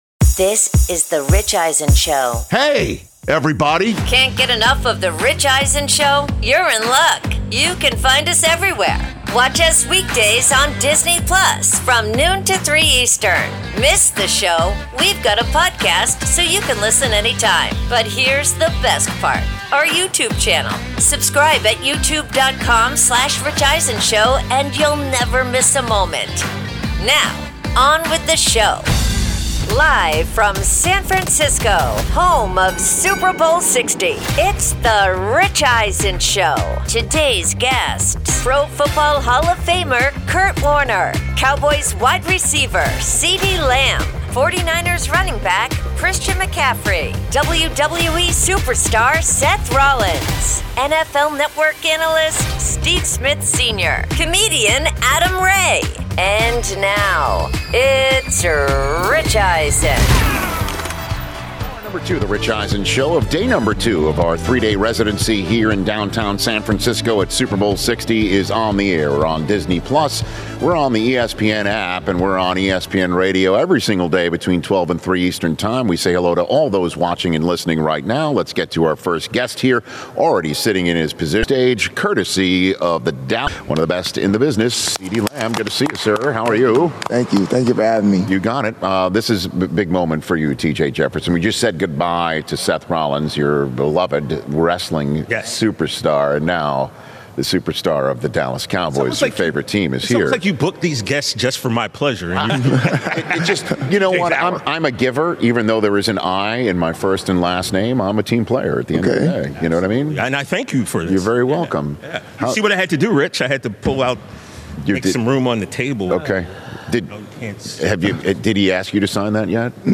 Hour 2: Live from Super Bowl LX with Cowboys WR CeeDee Lamb & 49ers RB Christian McCaffrey